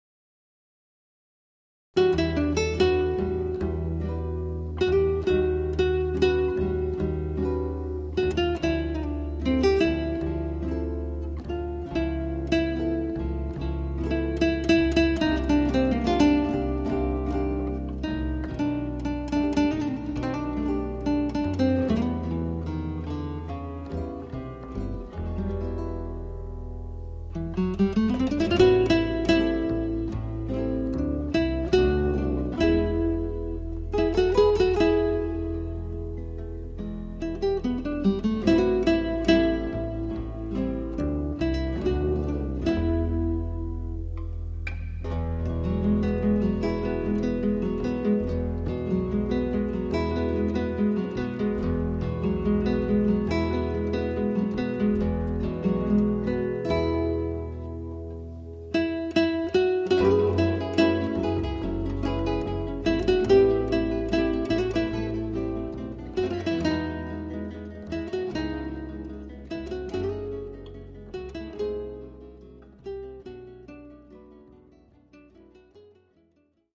keyboards
tenor saxophone
upright and electric bass
drums